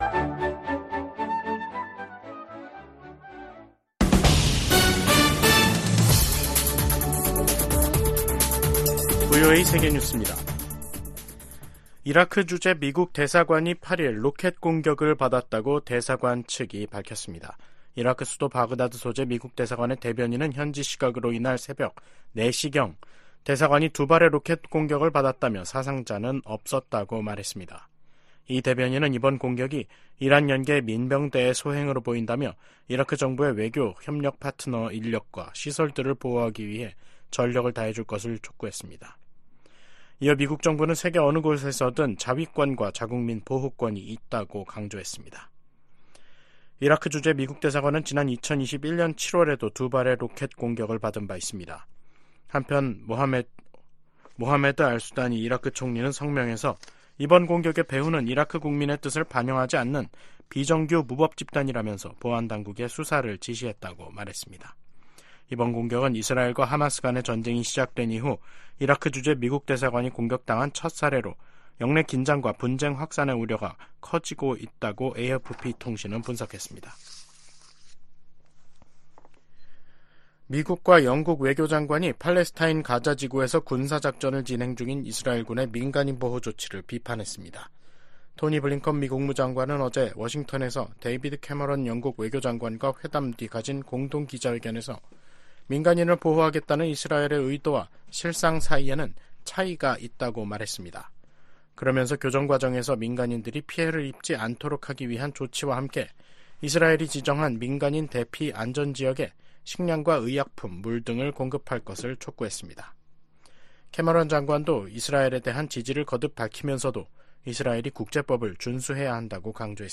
VOA 한국어 간판 뉴스 프로그램 '뉴스 투데이', 2023년 12월 8일 3부 방송입니다. 커트 캠벨 미 국무부 부장관 지명자가 상원 인준청문회에서 대북 억지력 강화의 필요성을 강조했습니다. 북한 정권의 불법 사이버 활동을 차단하기 위한 미국과 한국, 일본의 외교 실무그룹이 공식 출범했습니다. 미 상·하원이 9천억 달러에 달하는 내년 국방수권법 최종안을 공개했습니다.